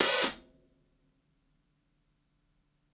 ROOMY_OPEN HH_1.wav